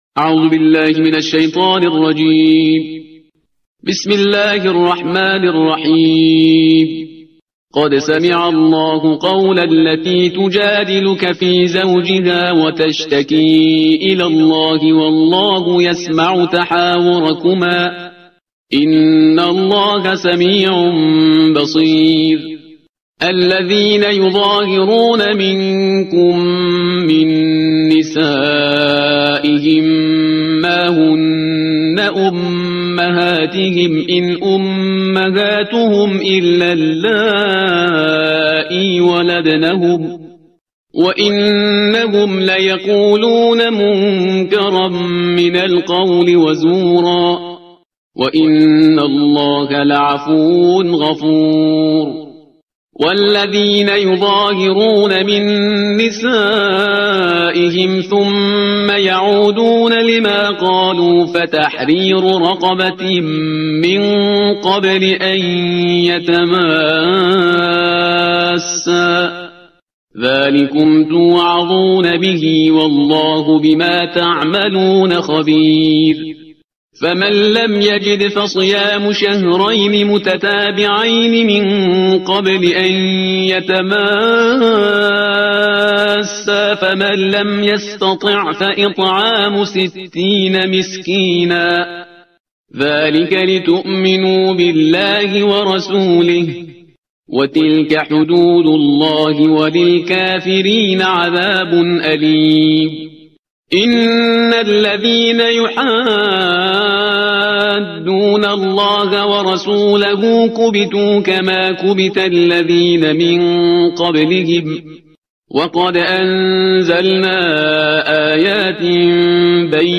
ترتیل صفحه 542 قرآن